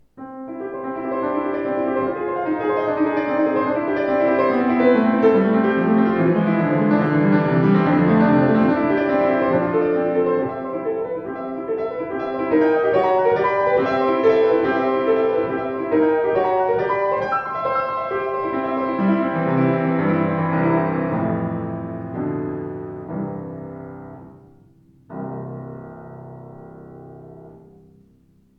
Voller, gestaltungsfähiger Klang, angenehm flüssige Spielart.